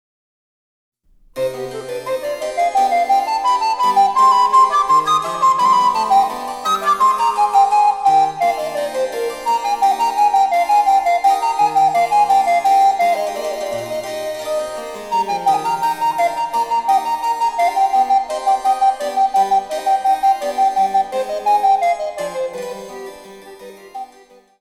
（ピッチはすべてモダンピッチ。
課題　１　伴奏スタート！